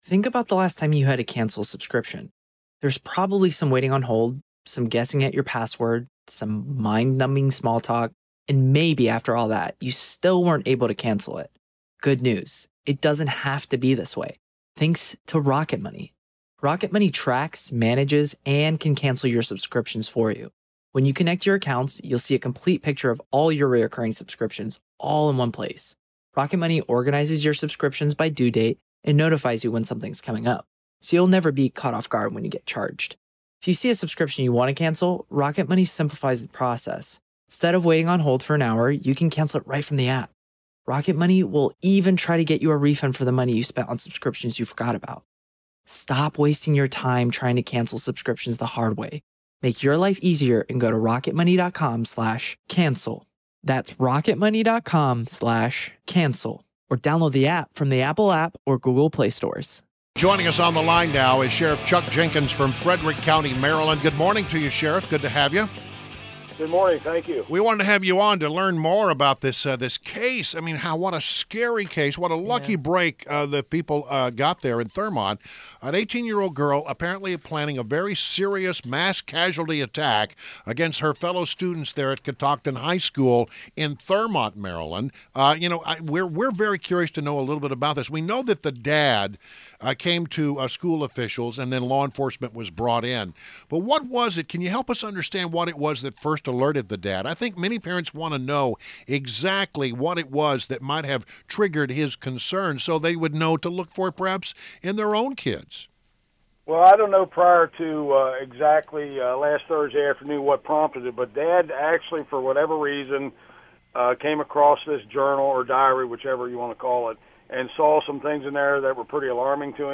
WMAL Interview - Sheriff Chuck Jenkins - 03.28.17